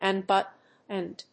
• / `ʌnbˈʌtnd(米国英語)